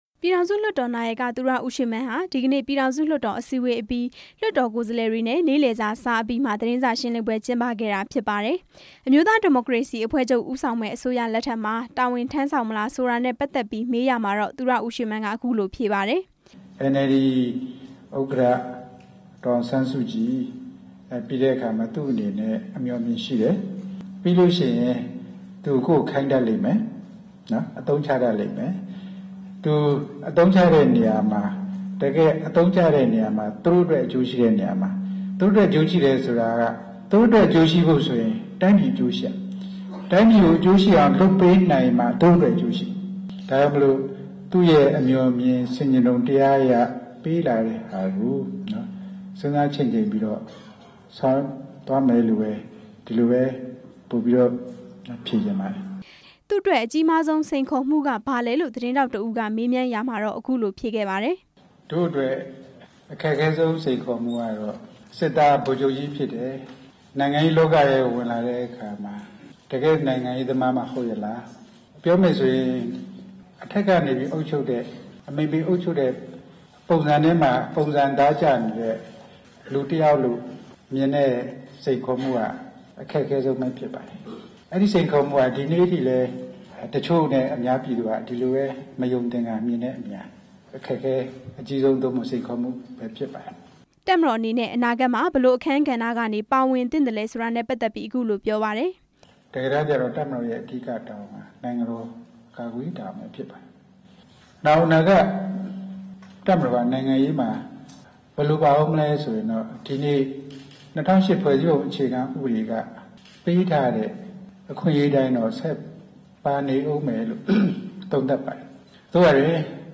သူရဦးရွှေမန်းရဲ့ လွှတ်တော်နောက်ဆုံးနေ့ သတင်းစာရှင်းလင်းပွဲ
ပြည်ထောင်စုလွှတ်တော်နာယက သူရဦးရွှေမန်းဟာ ပြည်ထောင်စုလွှတ်တော် နောက်ဆုံးနေ့ အစည်းအဝေးအပြီး လွှတ်တော်ကိုယ်စားလှယ်တွေနဲ့ နေ့လည်စာ စားအပြီးမှာ သတင်းစာရှင်းလင်းပွဲ ကျင်းပခဲ့ပါတယ်။